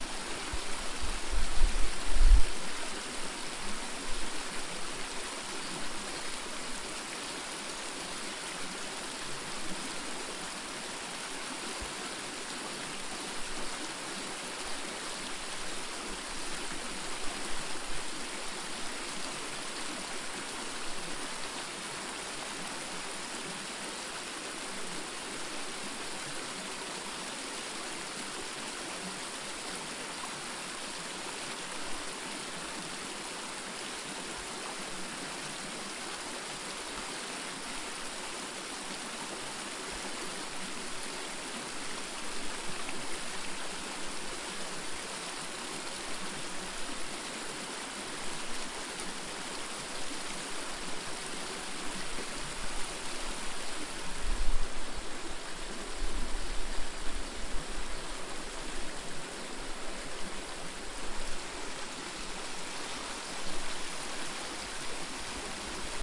现场录音 " 海浪拍打着码头
标签： 爱达荷州 音景 氛围 环境 地理标记 自然 放松 现场记录 表音文字
声道立体声